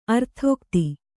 ♪ arthōkti